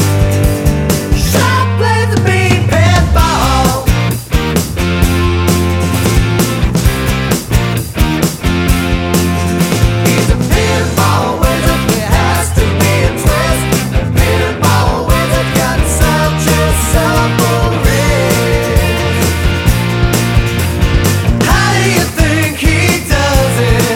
No Piano Pop (1970s) 4:09 Buy £1.50